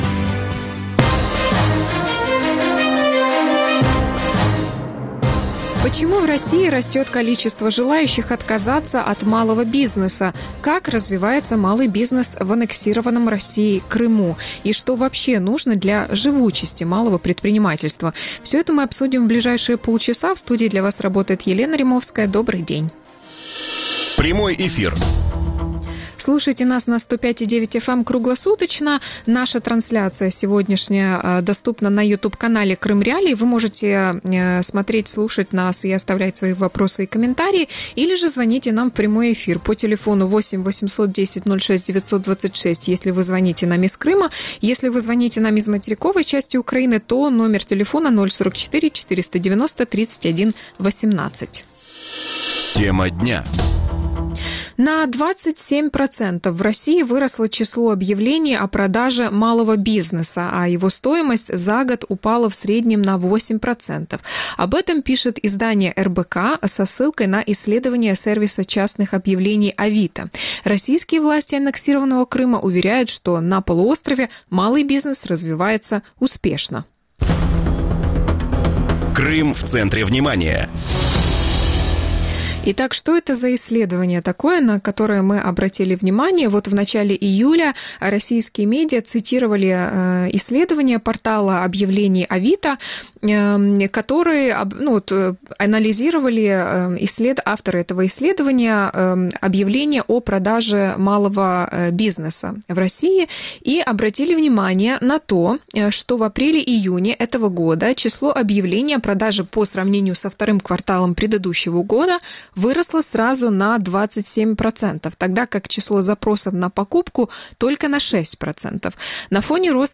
Гости эфира: Дмитрий Потапенко, российский бизнесмен, управляющий партнер компании Management Development Group;